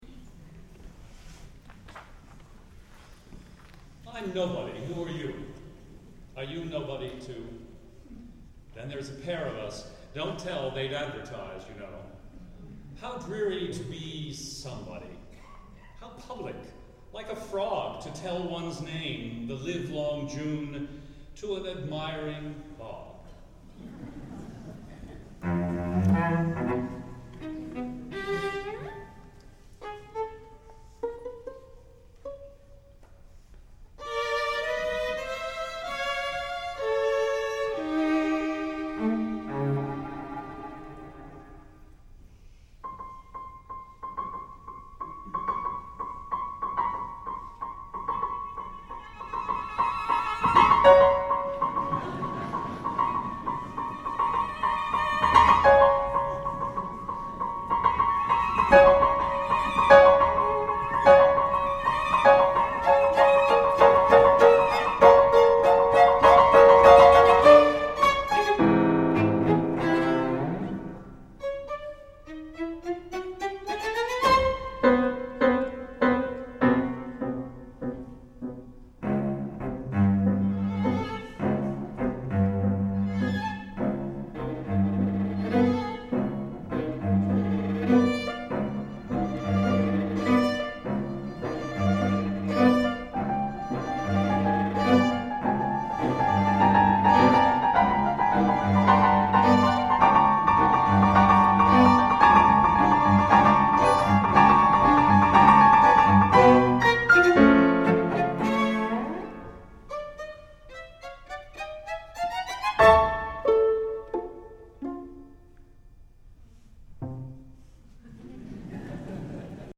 for Piano Quartet (2001)
And one last frog jumps into the pond.